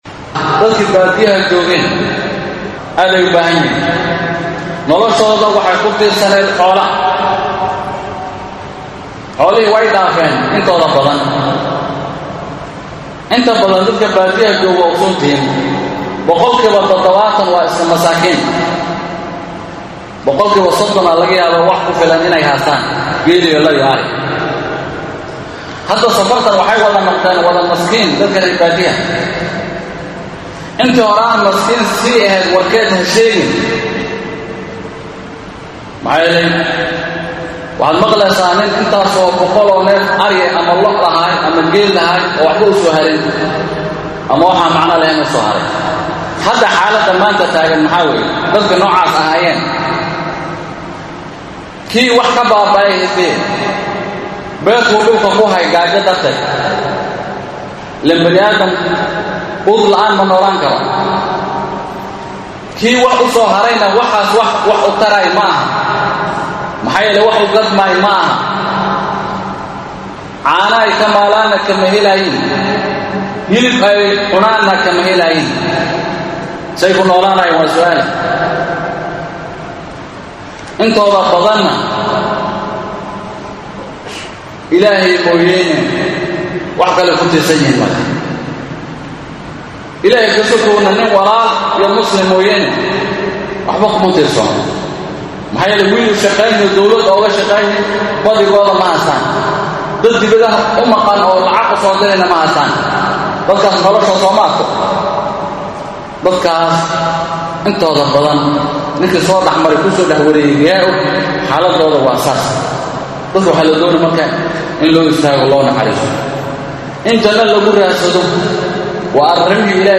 khudbadda Jimcada